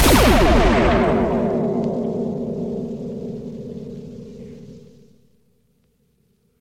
sniper.ogg